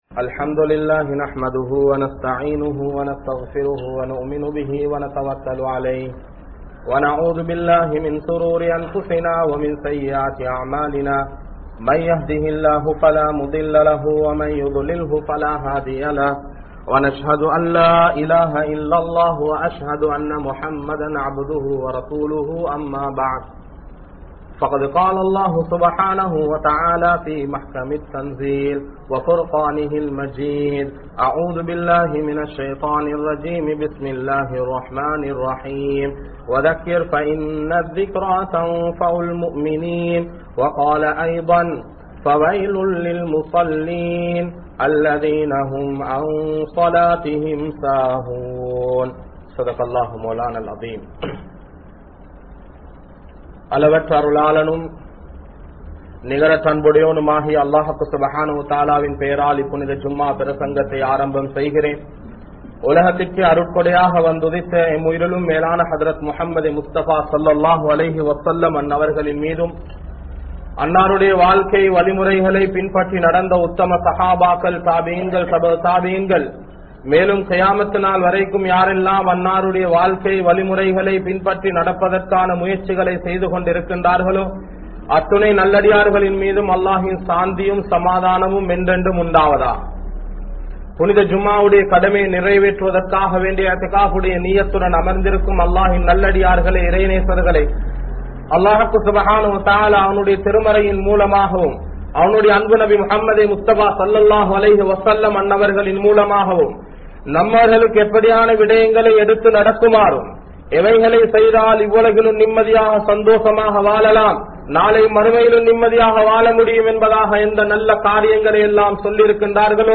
Tholuhai (தொழுகை) | Audio Bayans | All Ceylon Muslim Youth Community | Addalaichenai